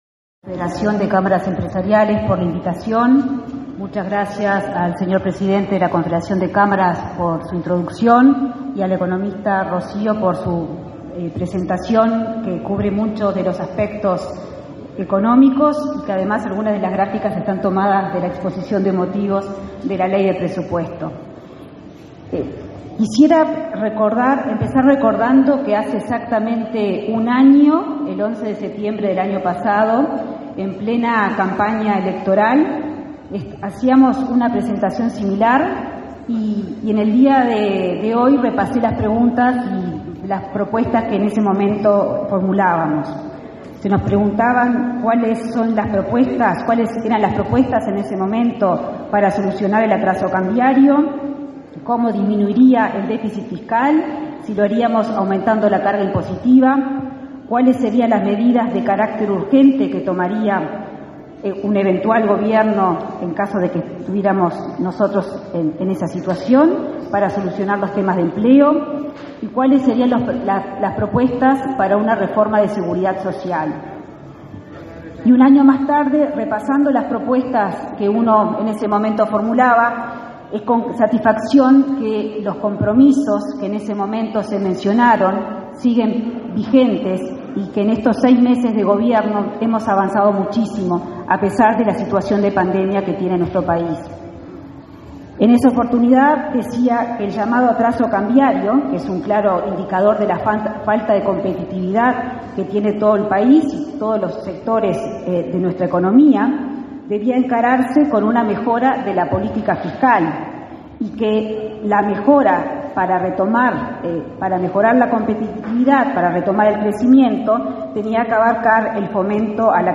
Gobierno presentó en la Expo Prado principales ejes para mejorar la competitividad y la inserción internacional